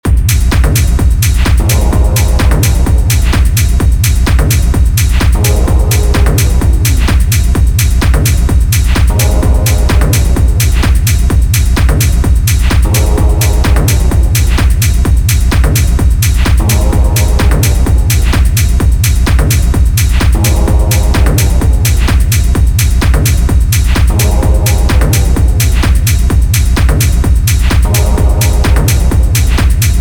в техно нужен именно прикольный саундизайн. вот к примеру начал делать техновый тречек, послушай как звучит бочкобас, думаешь это ревер? - нифига, вообще без него бочкобас выкручен.